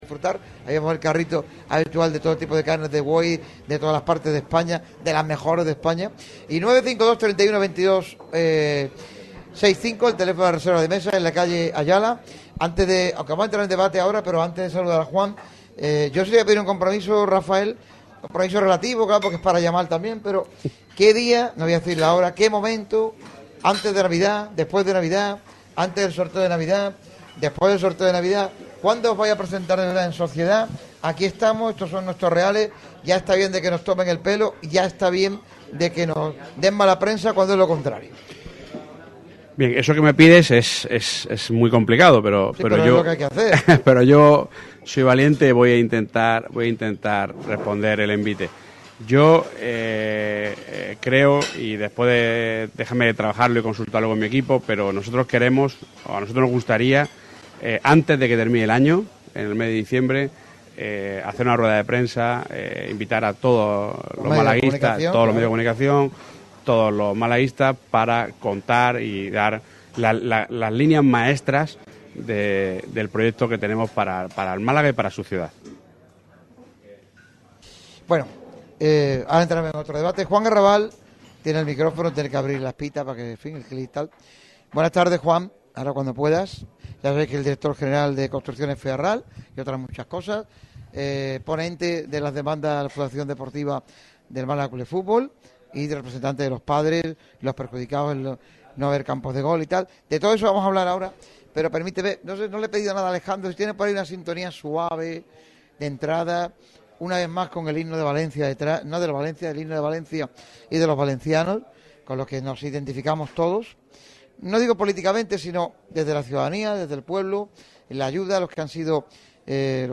El Asador Iñaki ha sido testigo de una nueva edición del programa matinal de Radio MARCA Málaga. La emisora del deporte malagueño se mudado durante las dos horas y media de programa al restaurante de moda en cuanto a carne en la capital de la Costa del Sol.